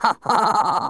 Worms speechbanks
laugh.wav